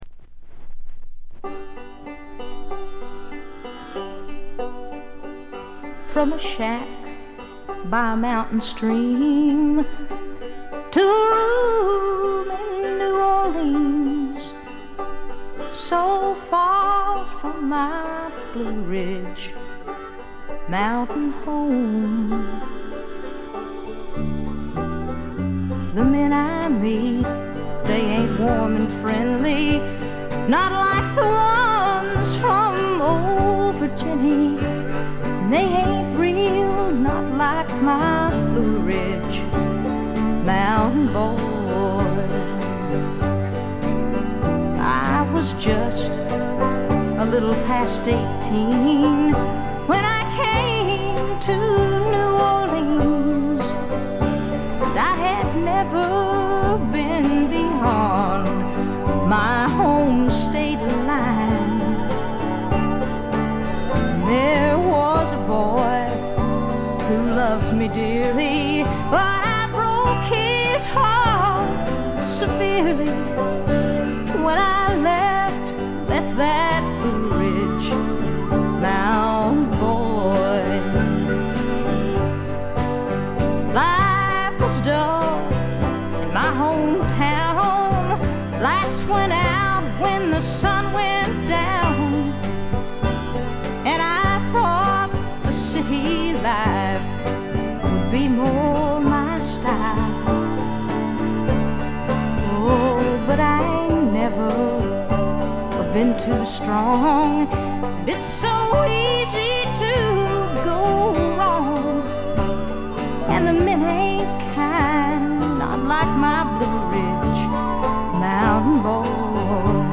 バックグラウンドノイズがほぼ常時出ており静音部でやや目立ちます。
試聴曲は現品からの取り込み音源です。
Guitar, Harmonica, Vocals